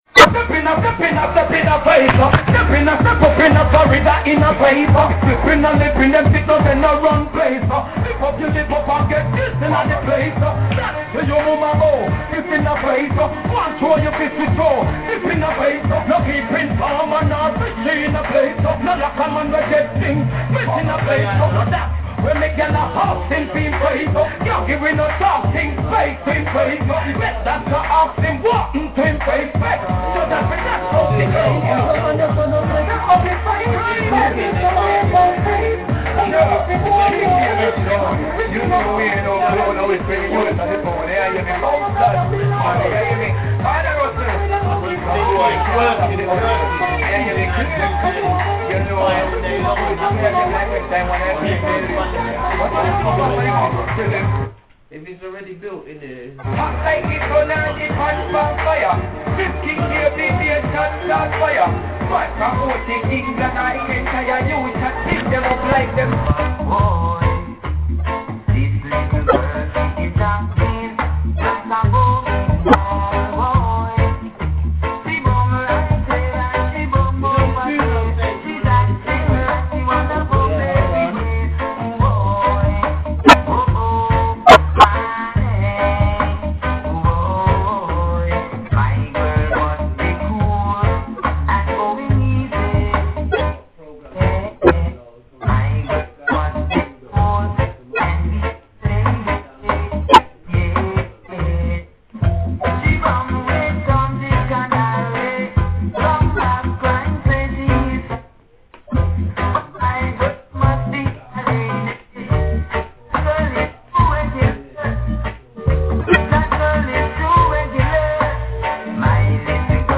big big new mix am loving these dubzz